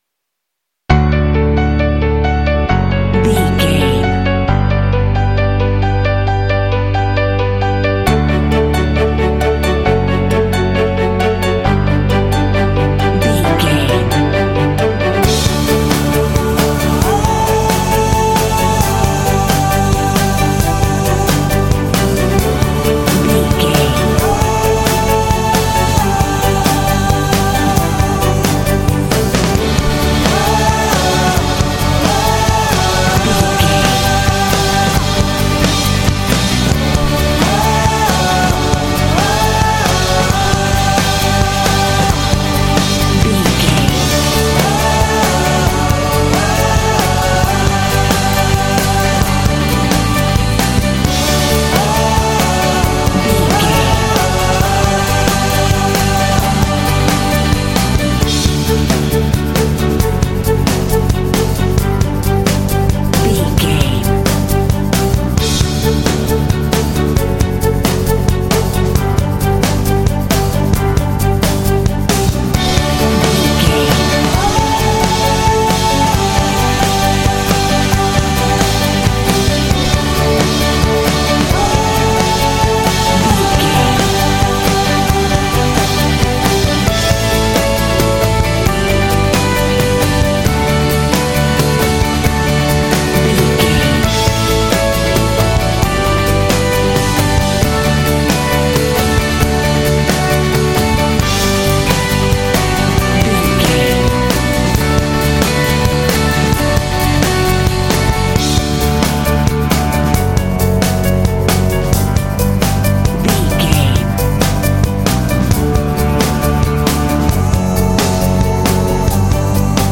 Bright and motivational music with a great uplifting spirit.
In-crescendo
Ionian/Major
uplifting
powerful
strings
orchestra
percussion
piano
drums
vocals
rock
contemporary underscore